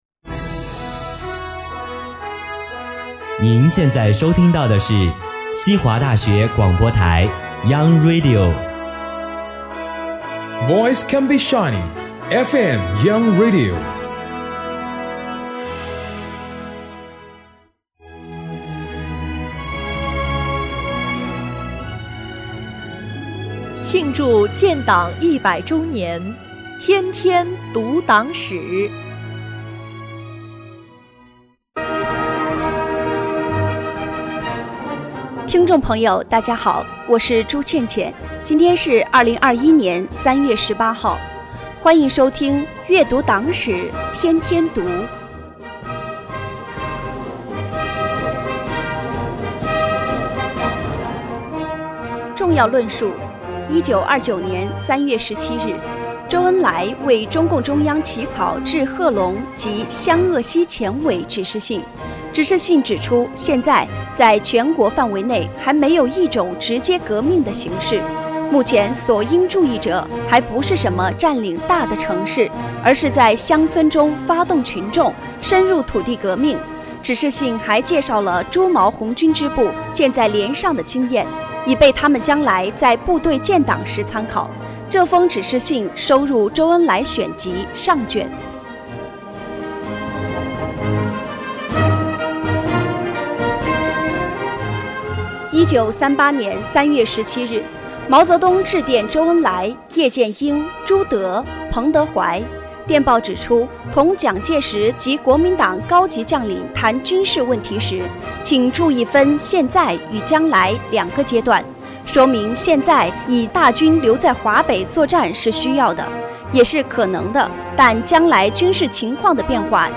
西华学子悦读党史